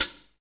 HAT FUZN 0CL.wav